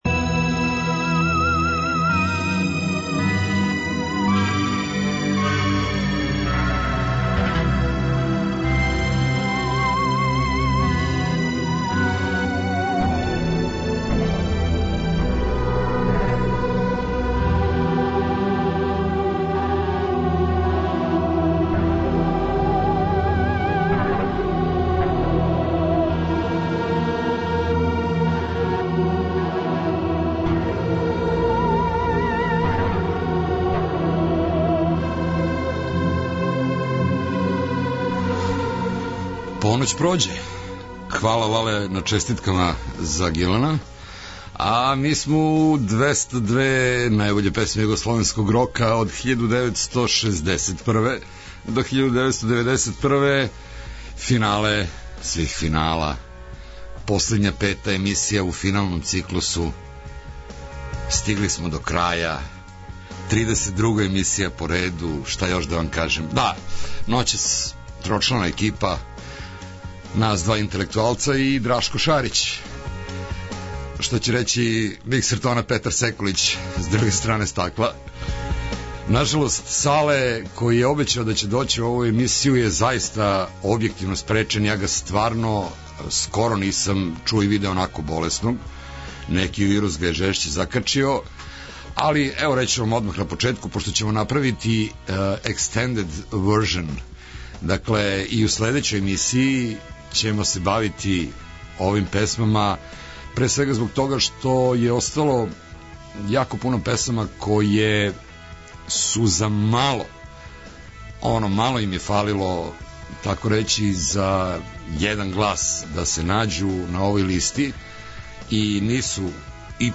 У петој, последњој емисији финала овог циклуса слушамо издања објављена у периоду 1988-1991.